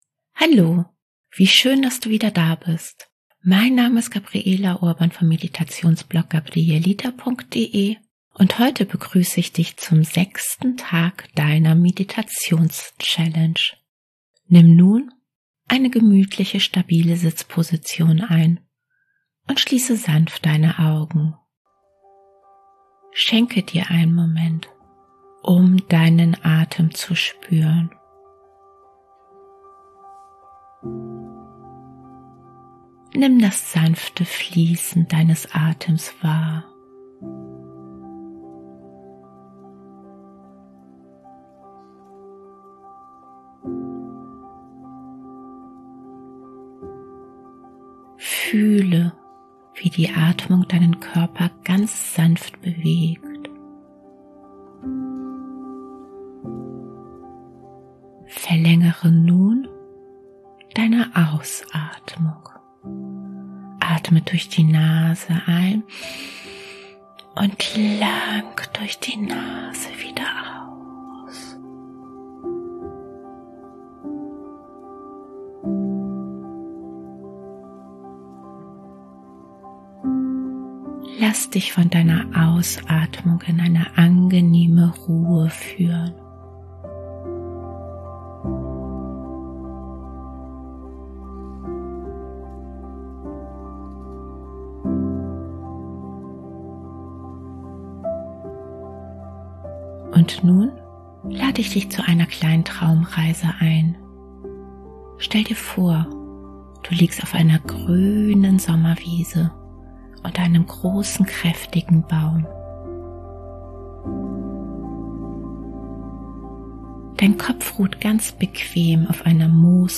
Meditations-Challenge
Traumreisen & geführte Meditationen